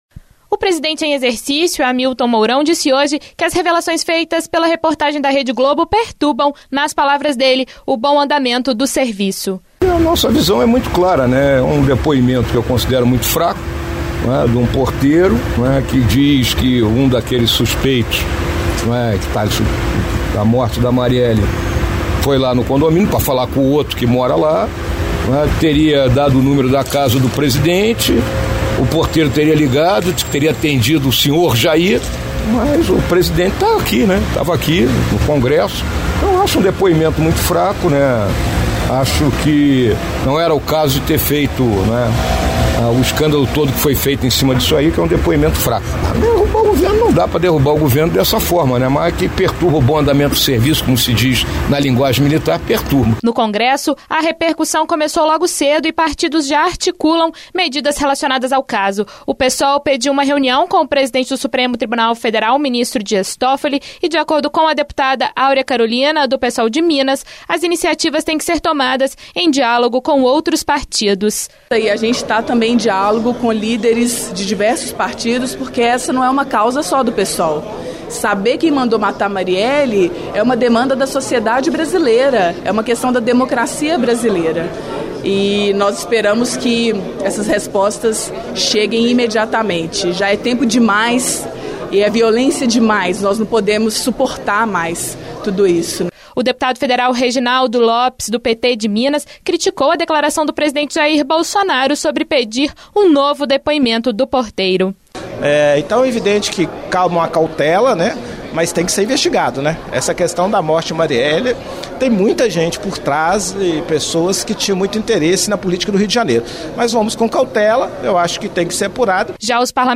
presidente em exercício, Hamilton Mourão fala sobre o caso